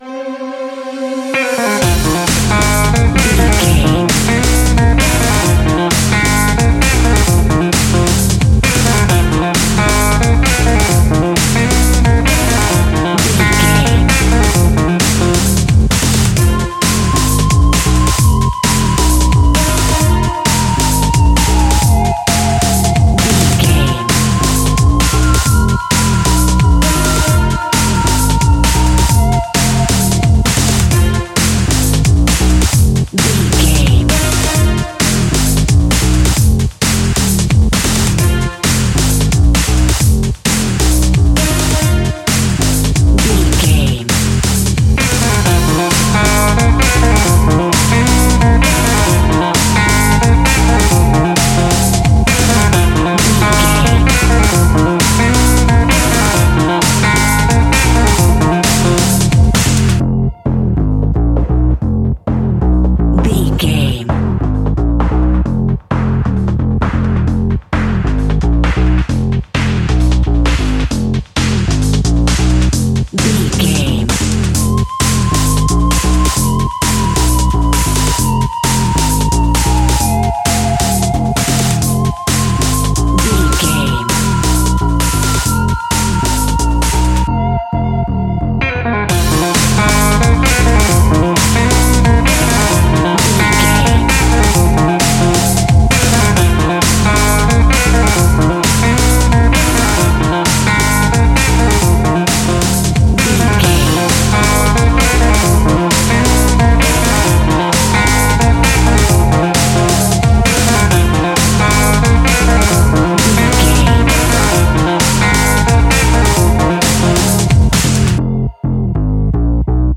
Thriller
Aeolian/Minor
Fast
groovy
energetic
funky
drum machine
electric guitar
suspense
ominous